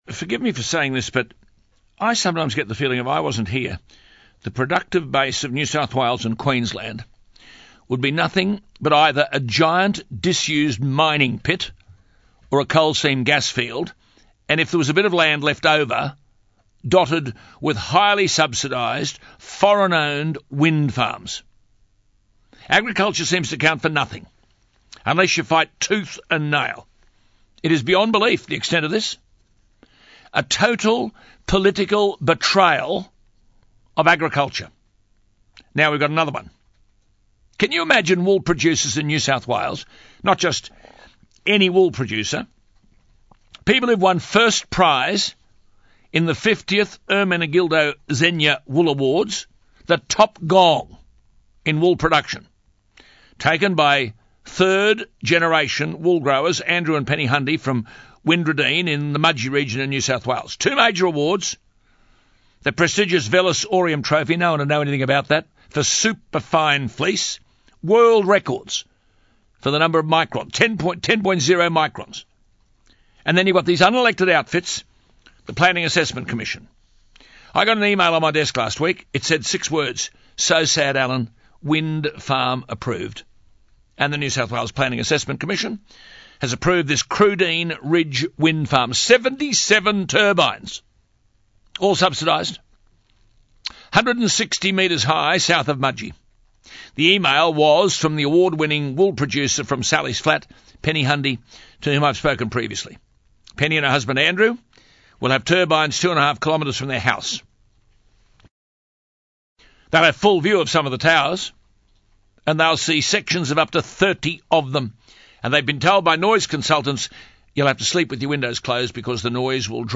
New South Wales is a hotbed of wind industry corruption, as Maurice Newman (the Business Adviser to former PM, Tony Abbott) laid plain during a recent interview with STT Champion, Alan Jones: Wind Industry Corruption in NSW: Maurice Newman Lifts the Lid